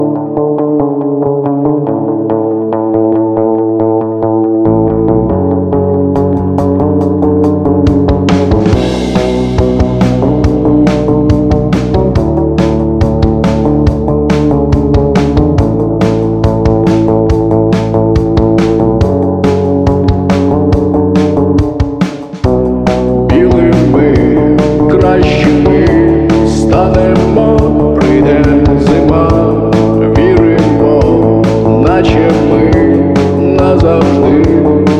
Жанр: Русская поп-музыка / Рок / Русский рок / Русские
# Rock